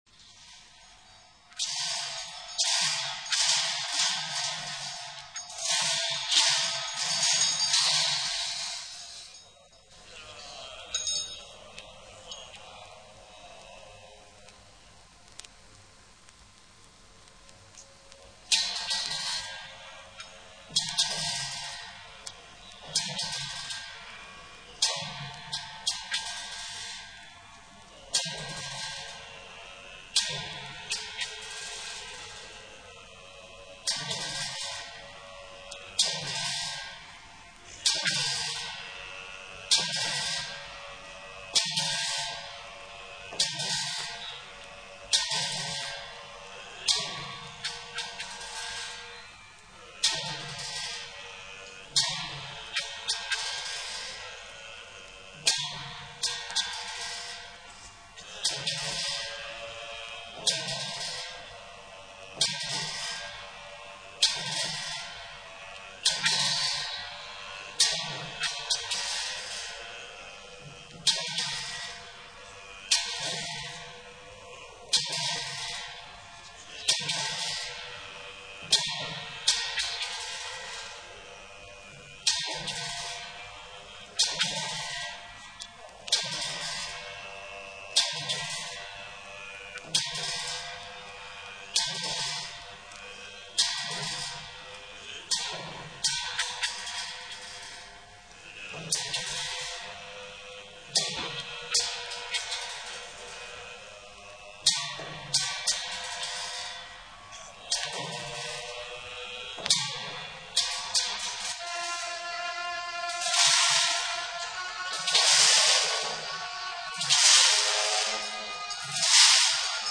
Ebéd után kicsit énekelnek, cintányéroznak, dobolnak, a legmeglepőbb méretű fúvós hangszereken játszanak.
Szertartás
Buddhista szertartás.